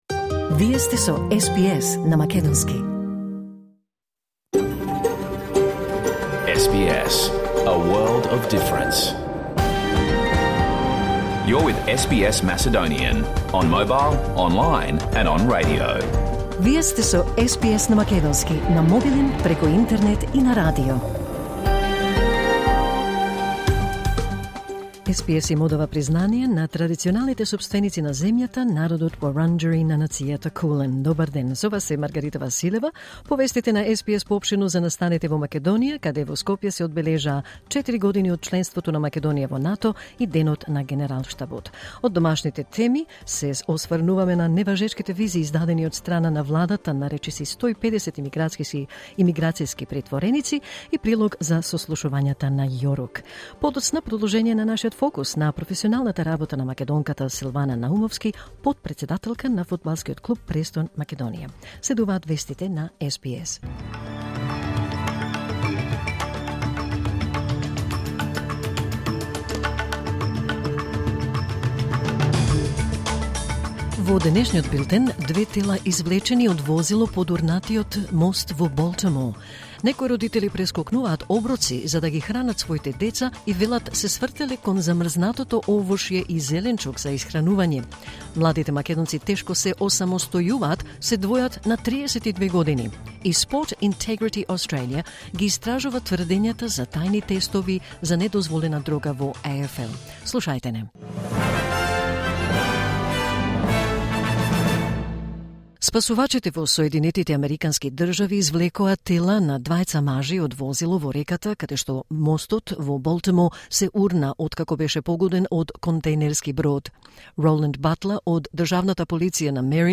SBS Macedonian Live on Air 28 March 2024